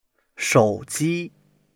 shou3ji1.mp3